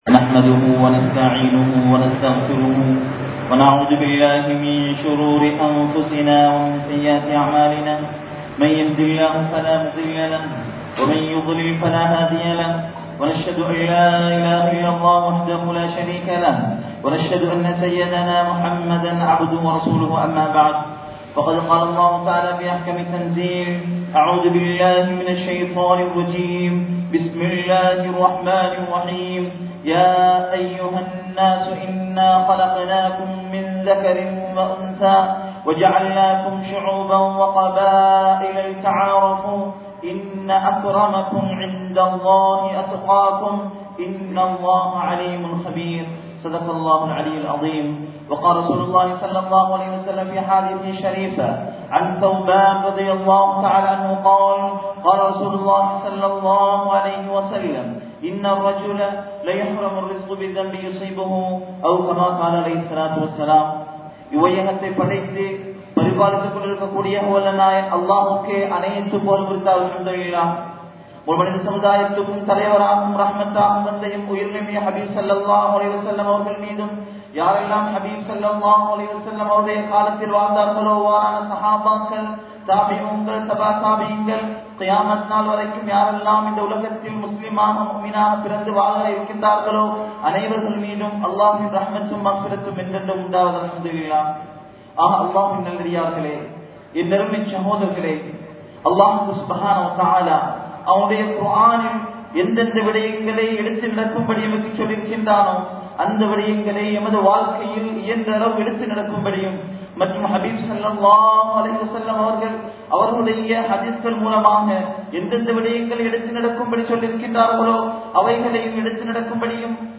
05 விடயங்கள் | Audio Bayans | All Ceylon Muslim Youth Community | Addalaichenai
Muhiyadeen Jumua Masjith